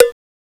Index of /m8-backup/M8/Samples/Fairlight CMI/IIX/PERCUSN1
COWBELOO.WAV